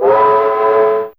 Index of /m8-backup/M8/Samples/Fairlight CMI/IIe/27Effects4
TrainWsl.wav